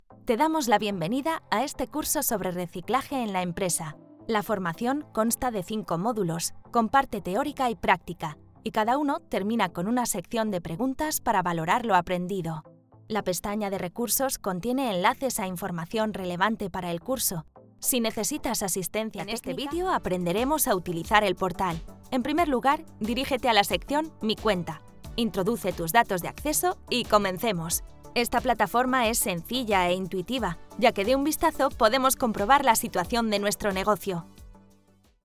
Commercial, Young, Natural, Friendly, Warm
Explainer